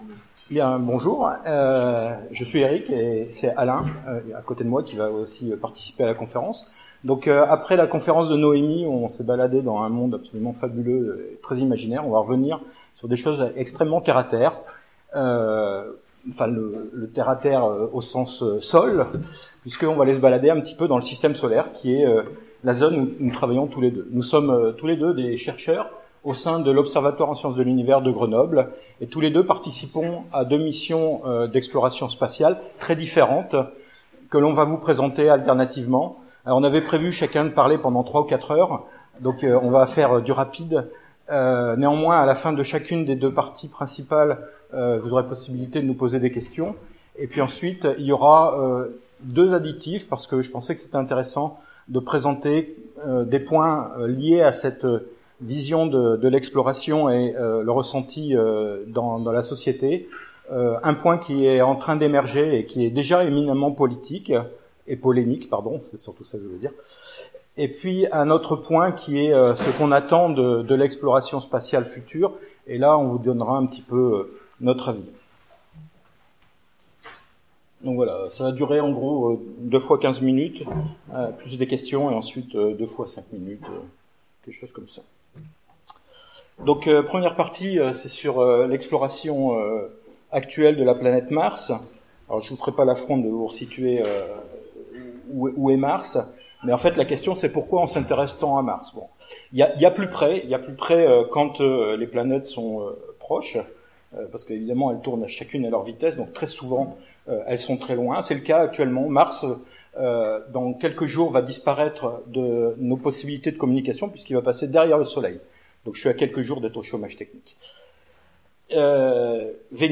Convention SF 2017 : Conférence martienne